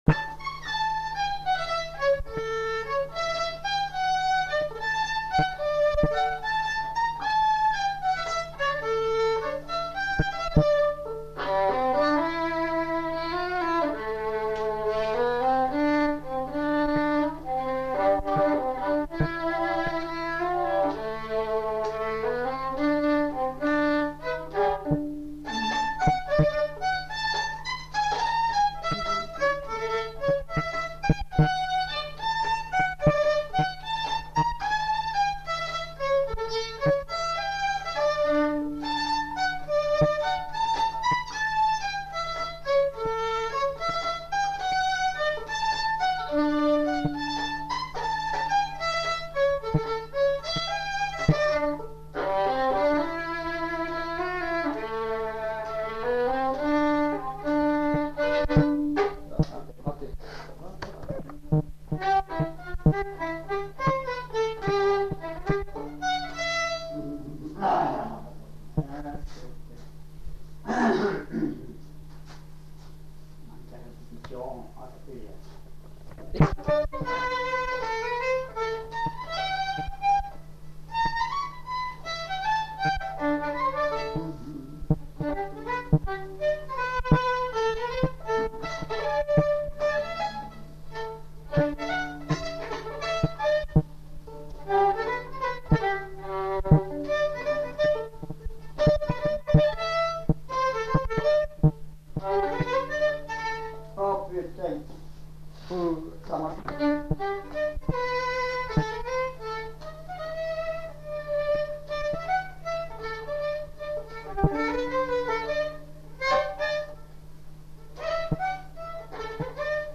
Lieu : Saint-Michel-de-Castelnau
Genre : morceau instrumental
Instrument de musique : violon
Danse : valse
Notes consultables : Recherche d'un air en fin de séquence.